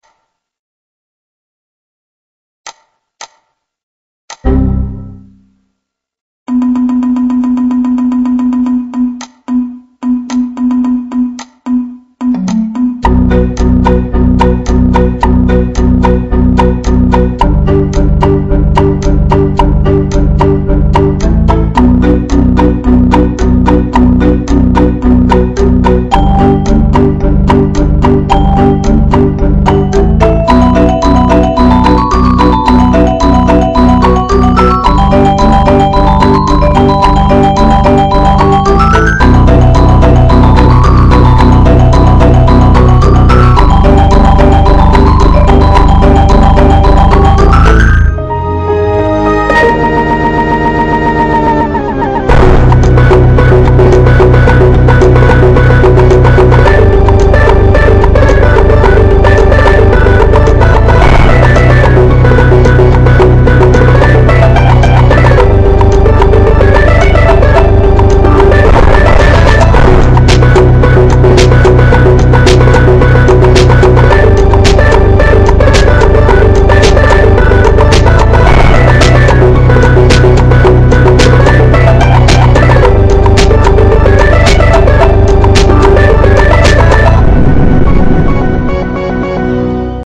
Компьютер меняет звук воспроизведения
kompyuter_menyaet_zvuk_vosproizvedeniya_p30.mp3